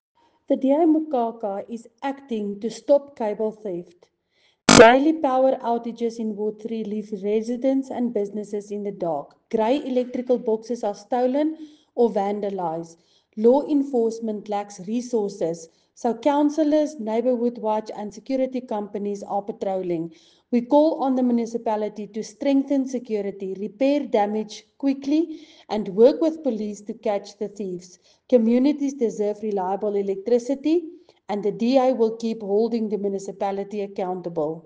Afrikaans soundbites by Cllr Linda Louwrens and